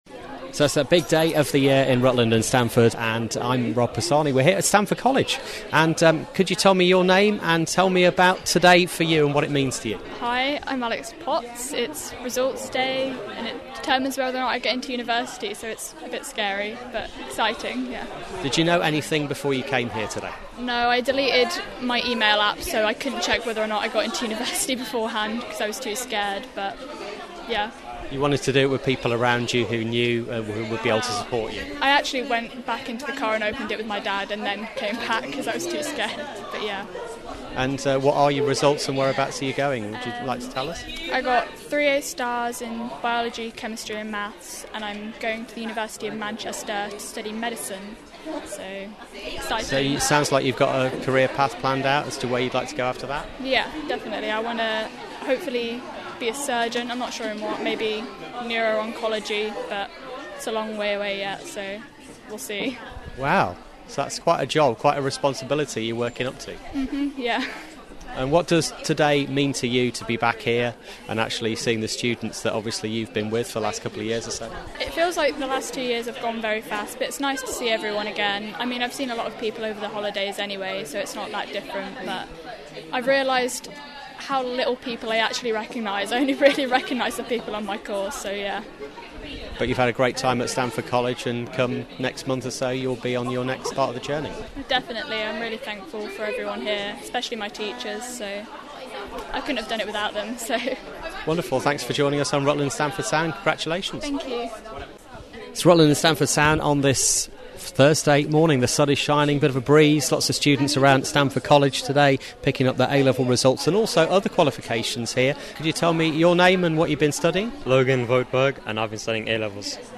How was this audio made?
Hear interviews with students and staff, including advice on clearing, from our live broadcast on A-level results day